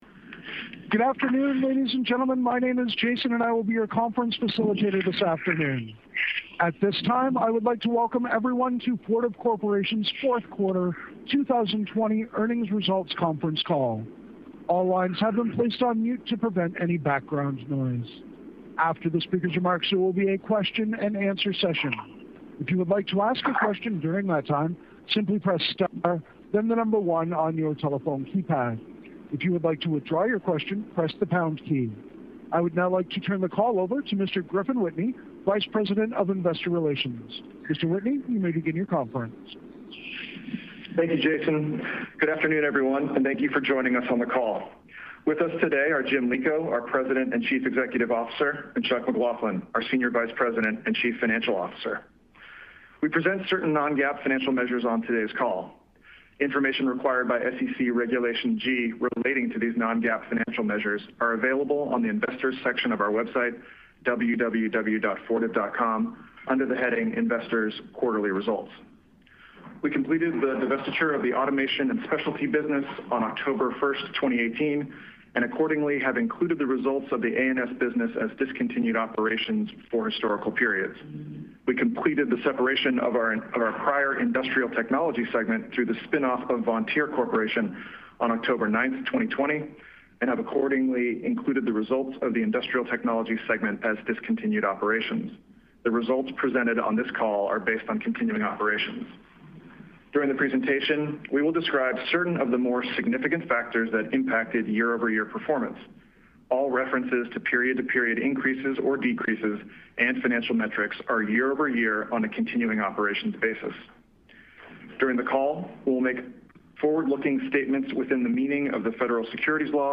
Fortive-Q4-2020-Earnings-Call-Audio.mp3